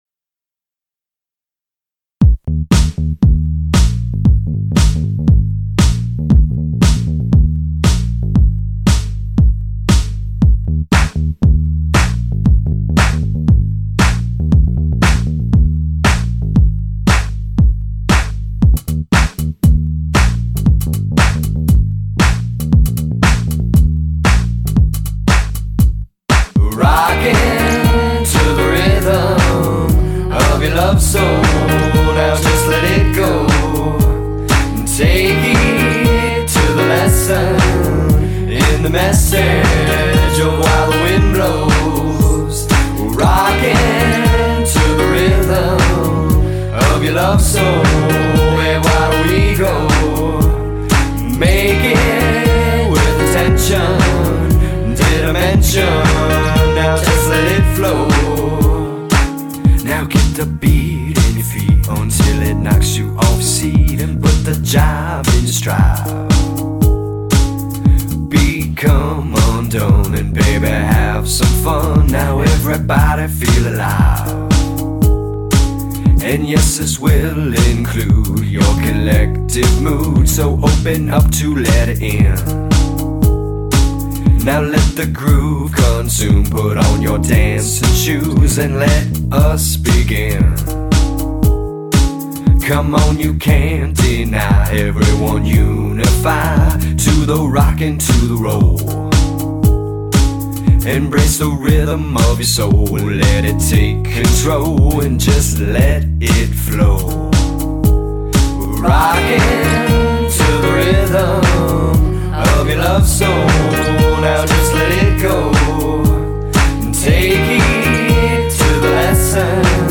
totally groovy, funky vibe
vocals, keys, guitar, bass, RC-50,  percussion
drums